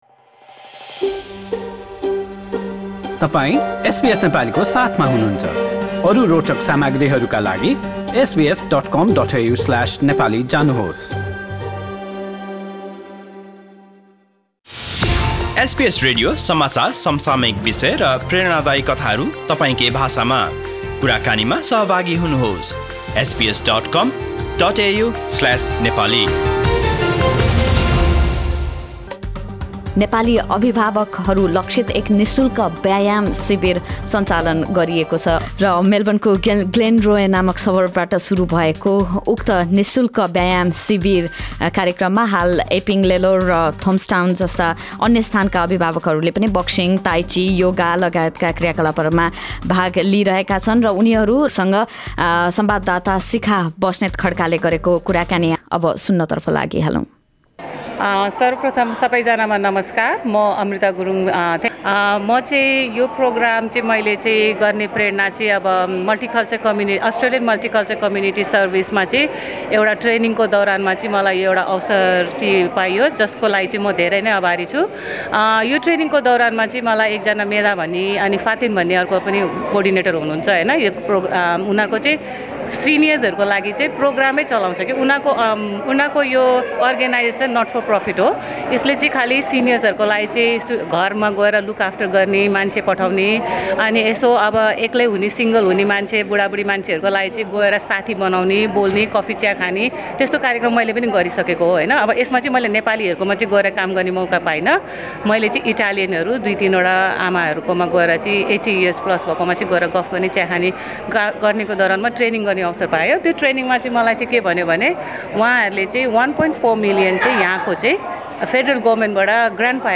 Conversation with participants of an exercise camp organised to help visiting Nepali parents in Melbourne, Australia with their physical and mental wellbeing.